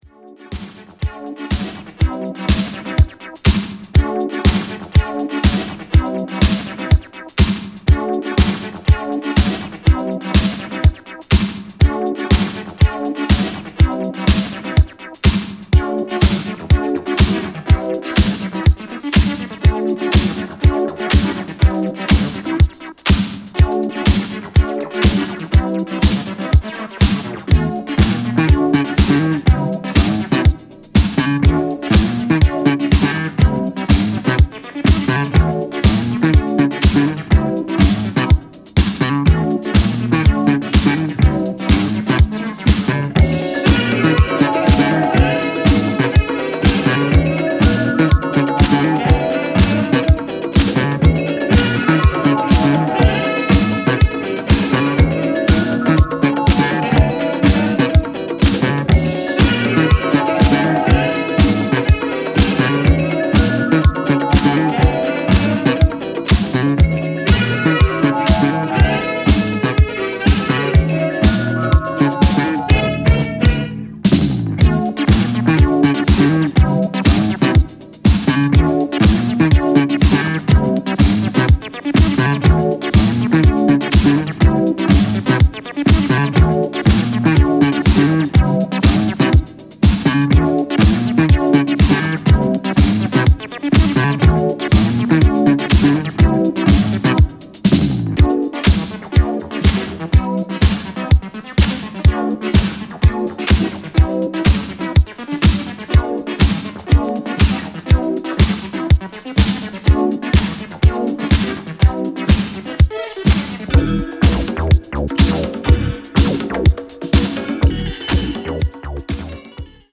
edits
live jam